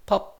snd_pop.ogg